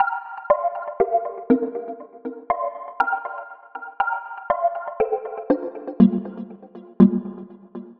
Night Chime 03.wav